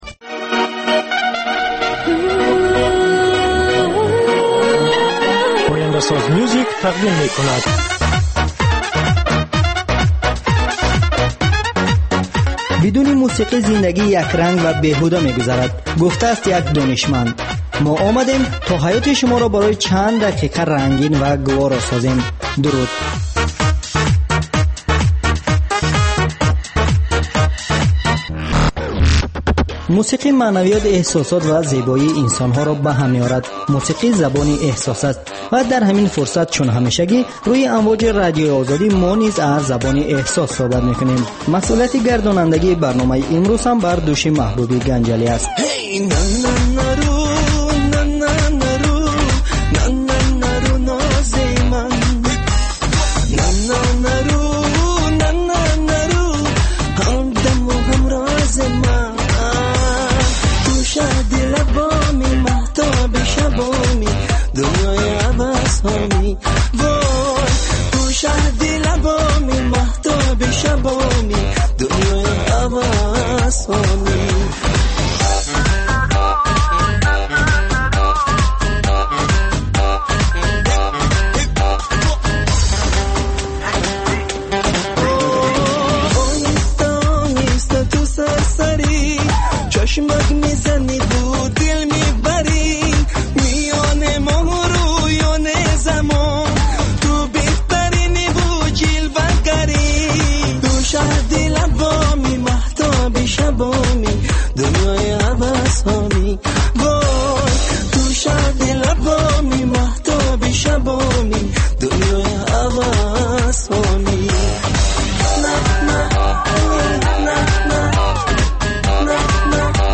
Барномаи мусиқӣ